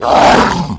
Звуки орков
Звук орка: крик в момент атаки